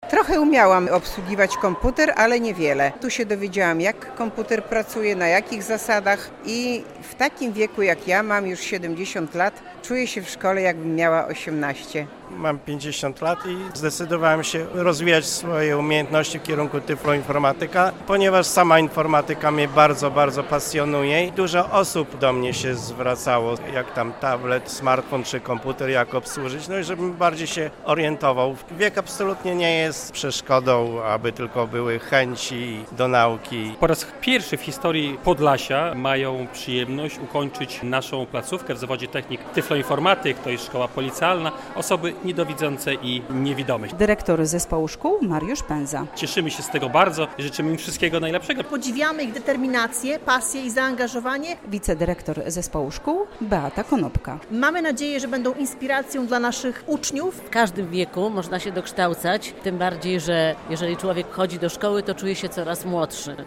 Pierwsi w historii regionu dorośli z niepełnosprawnościami odebrali świadectwa ukończenia szkoły średniej - relacja